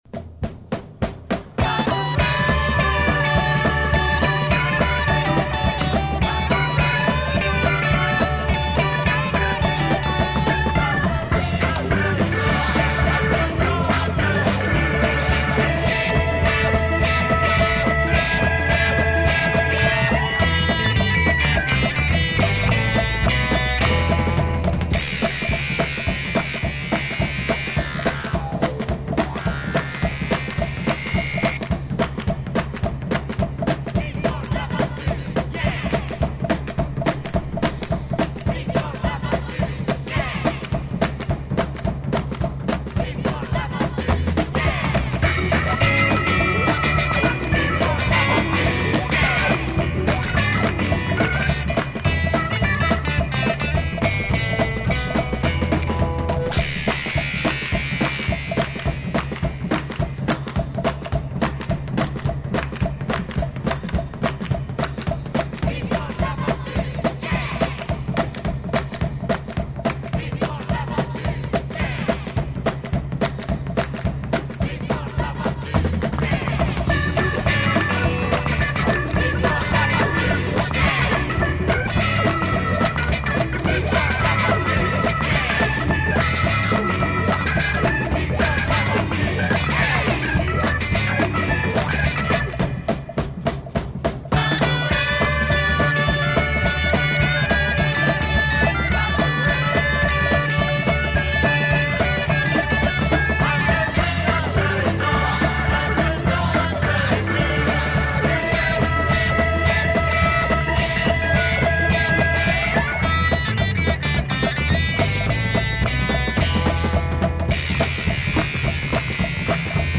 soundtrack
psych organ groove with vocals